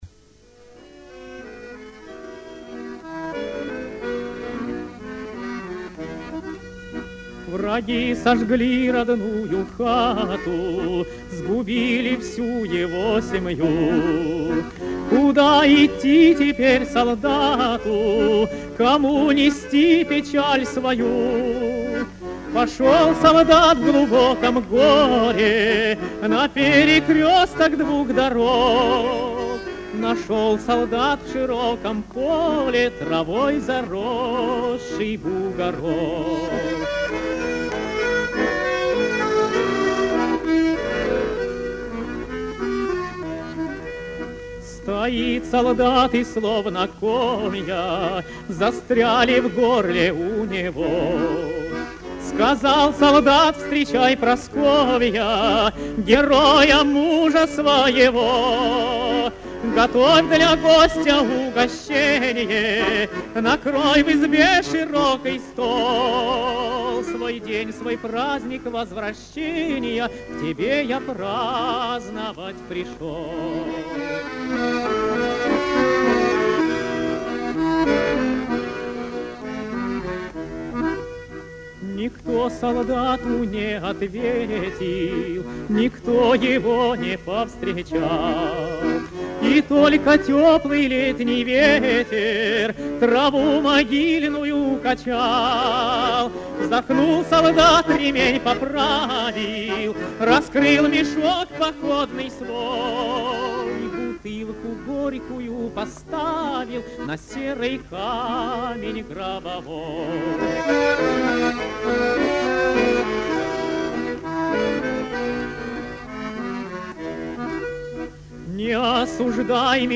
Одно из первых исполнений песни
трио баянистов